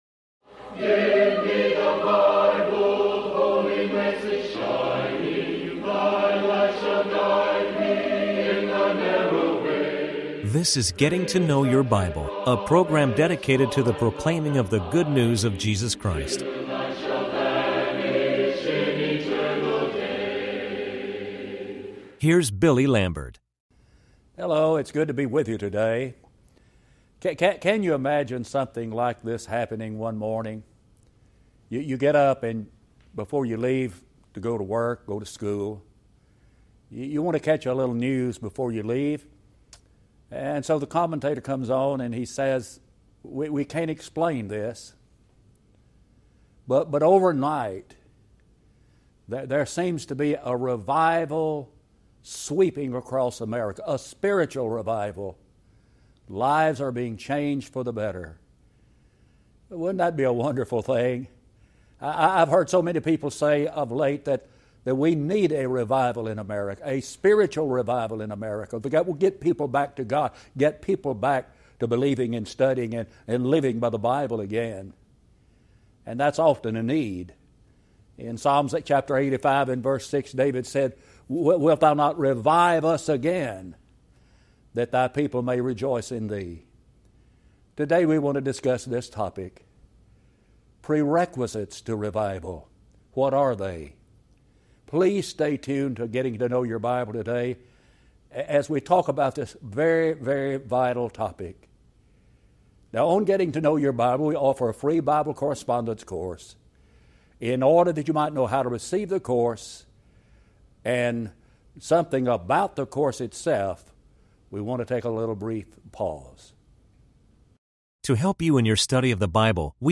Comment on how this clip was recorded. Getting To Know Your Bible is a TV program presented by churches of Christ, who are dedicated to preaching a message of hope and encouragement.